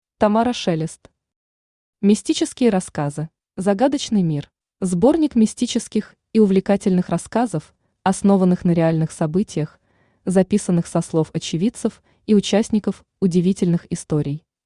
Аудиокнига Мистические рассказы | Библиотека аудиокниг